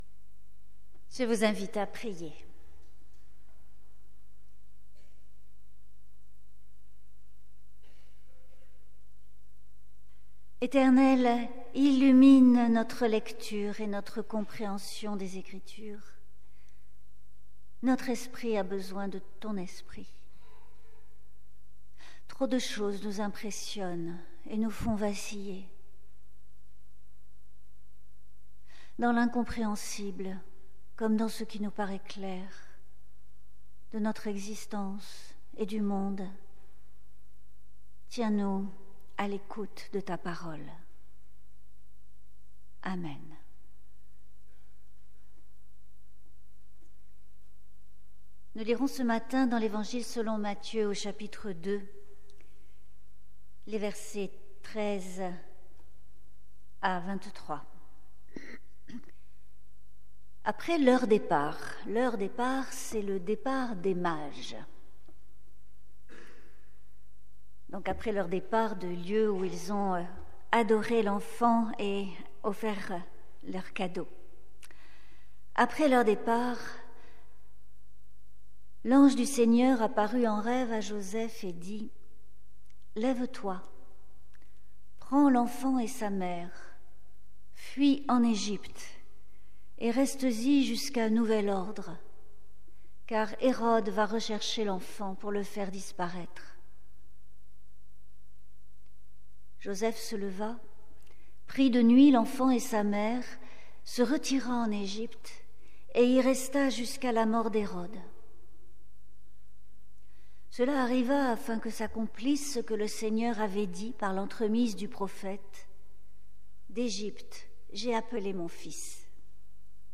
Conférence : L’islam iranien face aux luttes pour la laïcisation par le bas